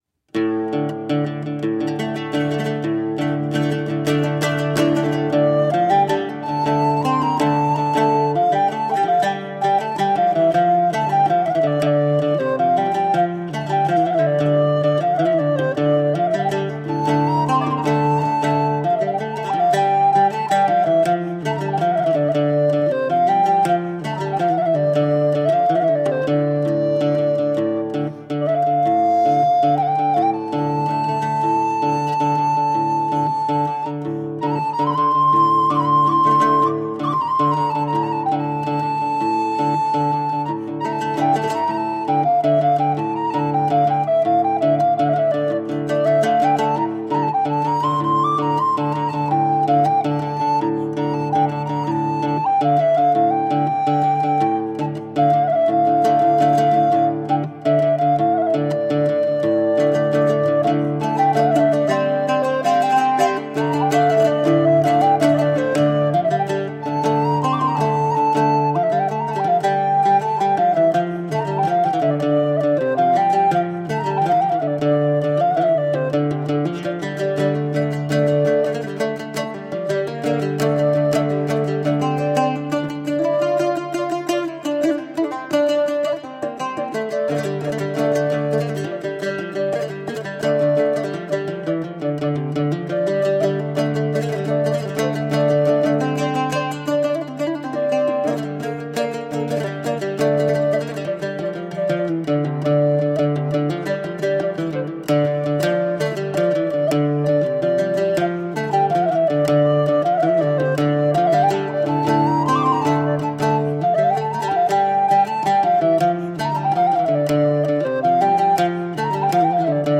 Medieval music from the 12th to the 15th centuries.